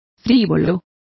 Complete with pronunciation of the translation of flighty.